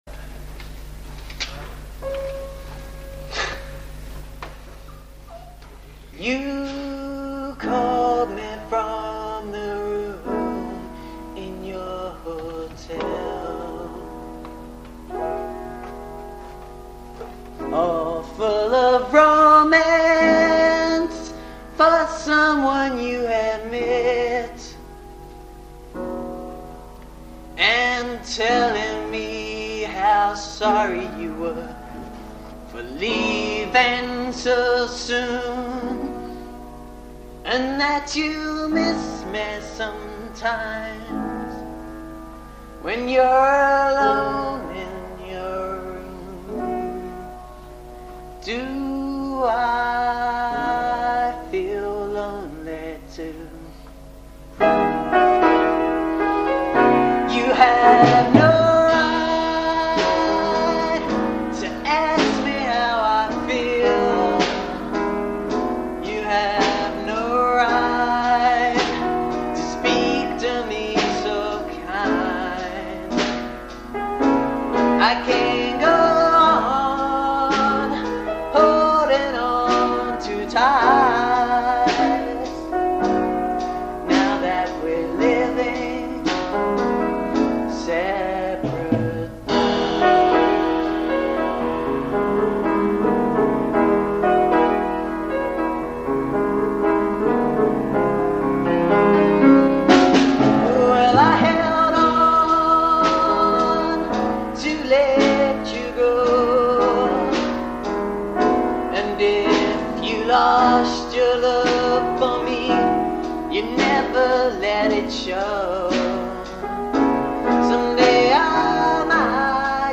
In these recordings, notice that there are never more than 3 instruments (including voice), cos the rules limited us to 3 people max.
• Piano
• Drums - [aaargh, forgot his name!!
Rehearsal Version (mp3) (recommended)